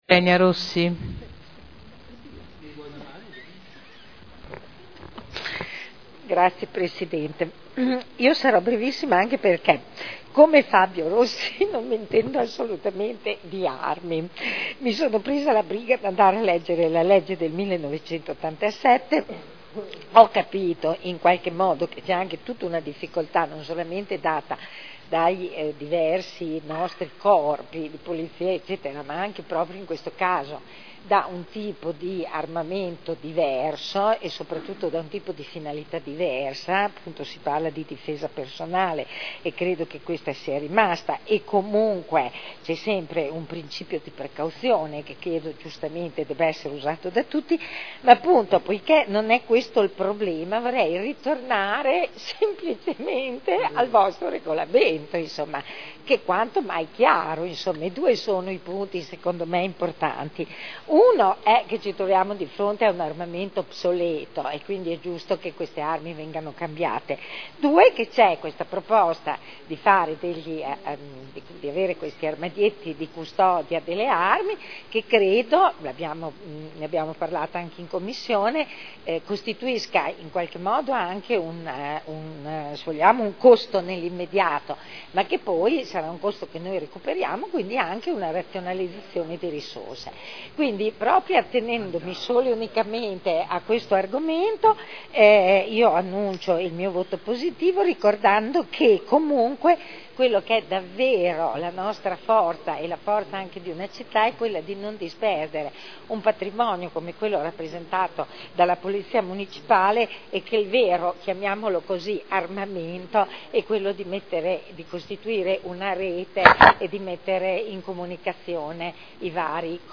Dichiarazione di voto.